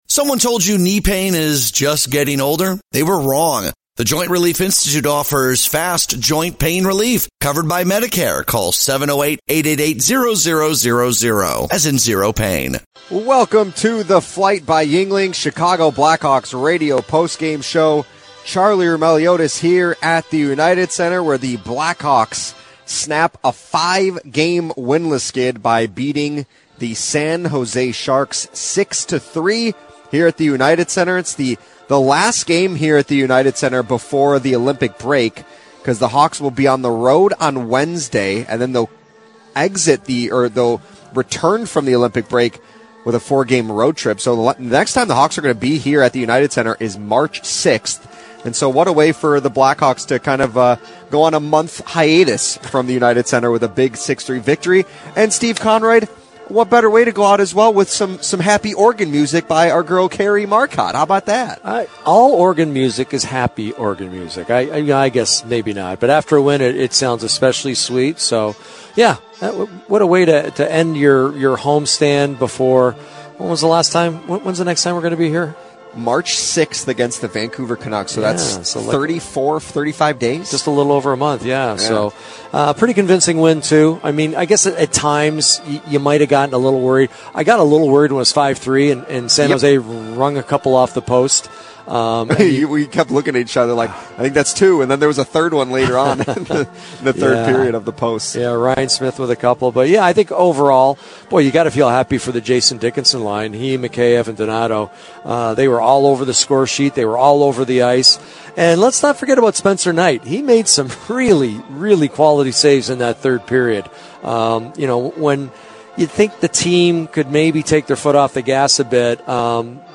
Later, we hear from Donato, Connor Murphy, Dickinson, and head coach Jeff Blashill.